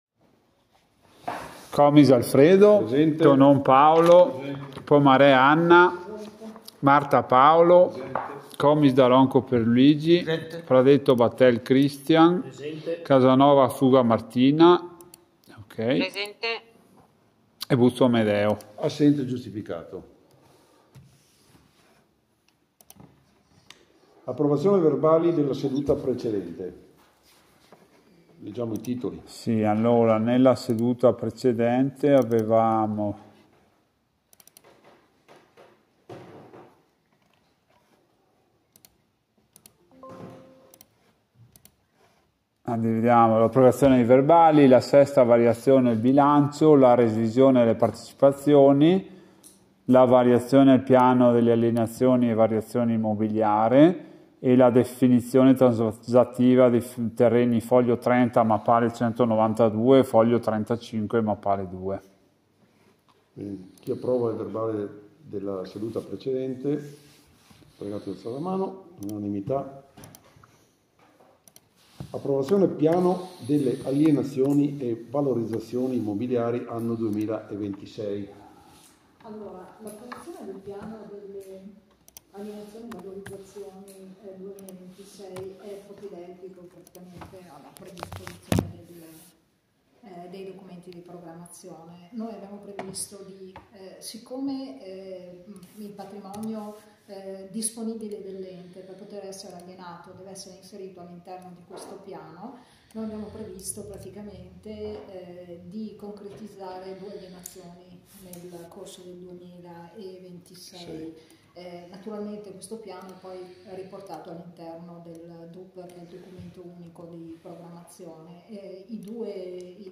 Registrazione Consiglio Comunale del 02/03/2026